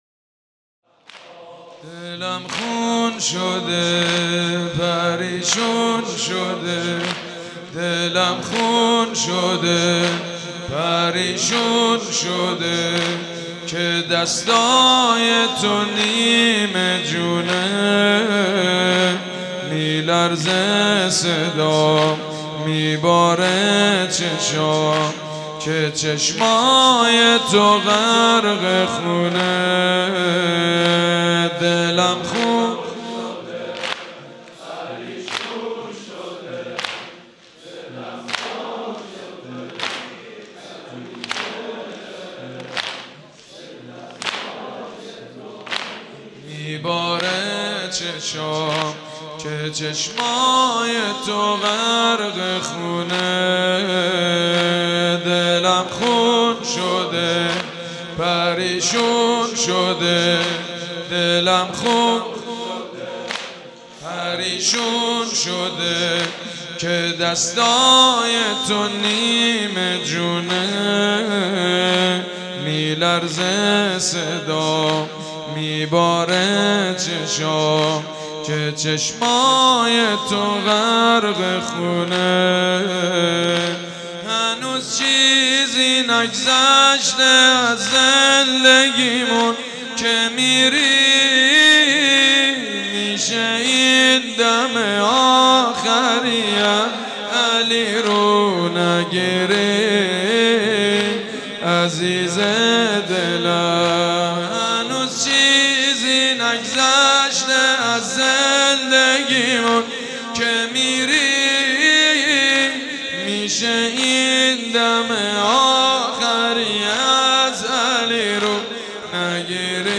زمینه.mp3